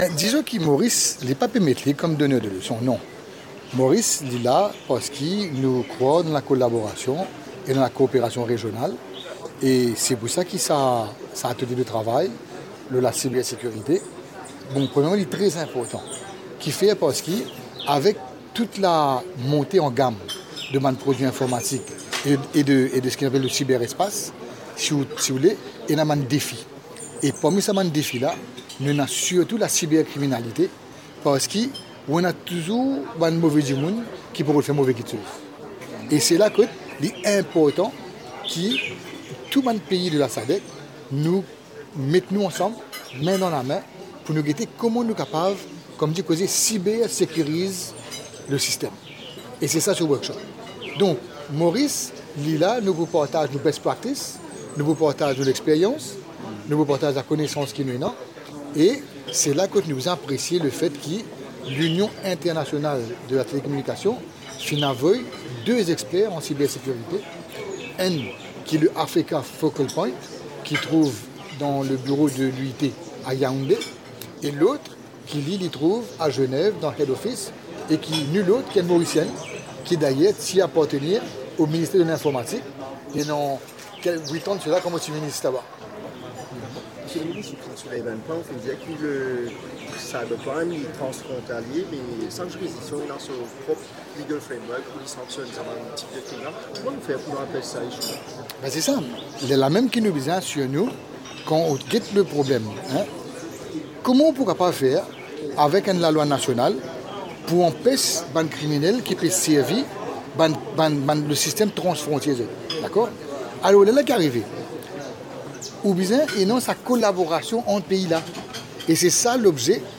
C’est ce qu’a déclaré le ministre des Technologies de l’information et de la communication (TIC), mardi 30 août. C’était lors de l’ouverture d’un atelier de travail de la SADC sur la cybersécurité à l’hôtel Intercontinental, Balaclava.